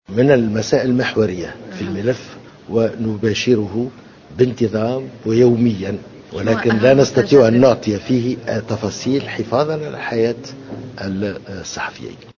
أكد وزير الخارجية الطيب البكوش في تصريح اعلامي اليوم الخميس 19 فيفري 2015 أن ليبيا فيها حكومتين واحدة في الشرق والأخرى في الغرب وكل واحدة لها شرعيتها.